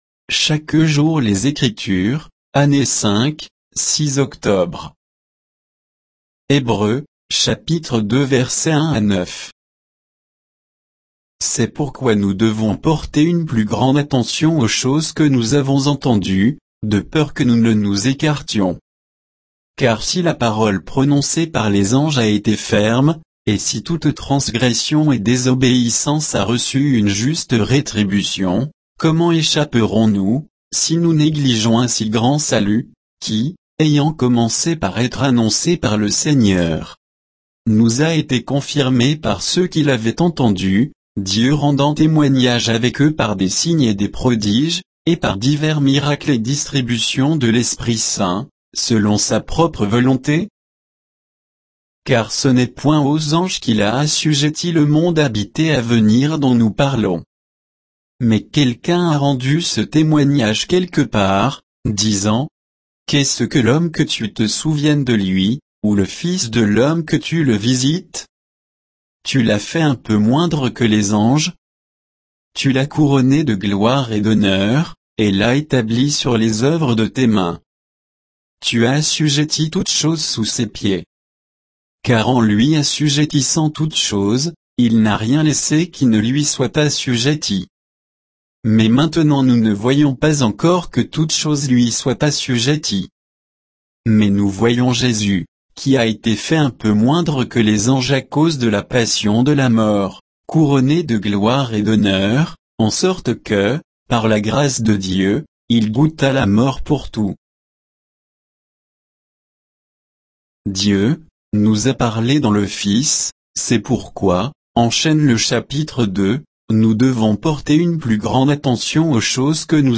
Méditation quoditienne de Chaque jour les Écritures sur Hébreux 2, 1 à 9